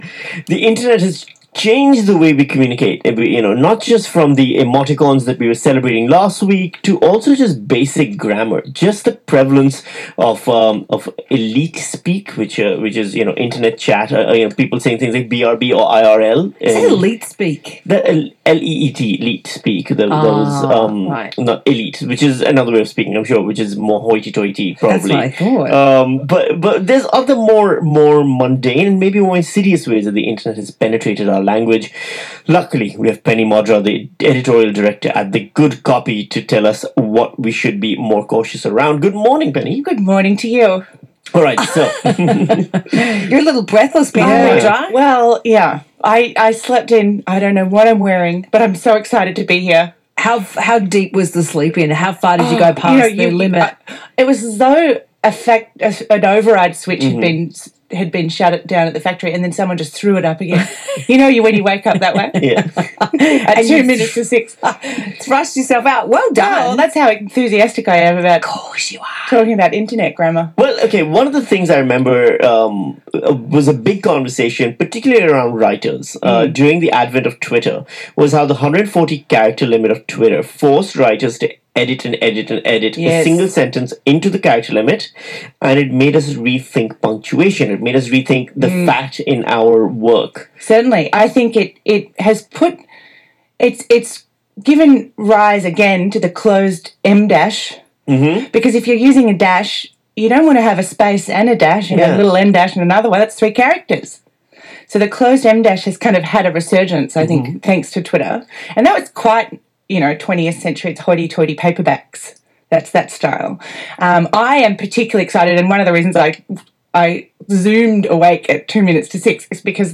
You can hear a discussion about how the internet is changing language, and whether or not so can start a sentence in this audio clip from the ABC Melbourne Breakfast show (the clip lasts for just over 9 minutes).